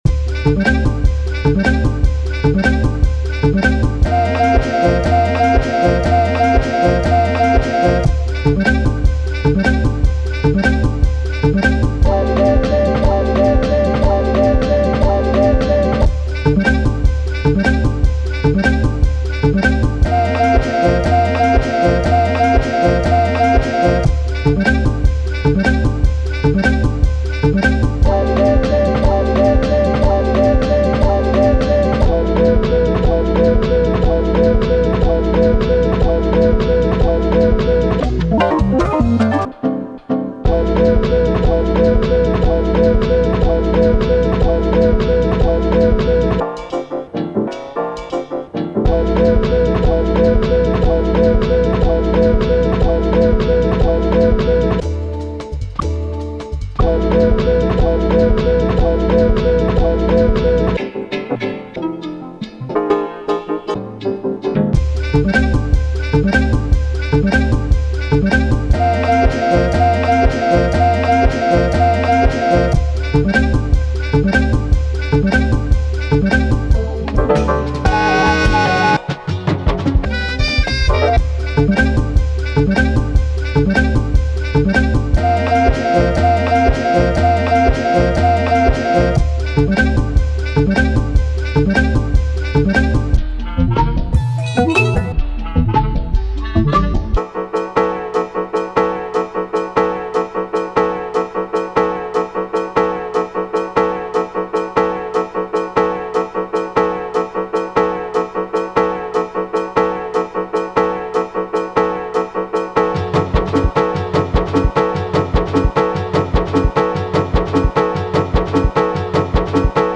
Just added a bit of reverb and Arturia’s Tape mello-fi.
All done in Ableton.
AHHHHHHHH WHAT THAT WAS A HOT BEAT!